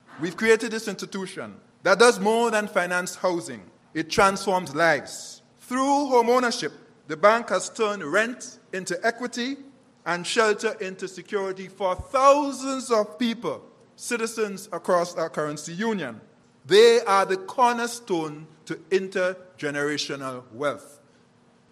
Those were the words of Governor of the Eastern Caribbean Central Bank (ECCB), Mr. Timothy Antoine. His comments were made at a recent event to officially launch the 30th anniversary celebrations of the Eastern Caribbean Home Mortgage Bank.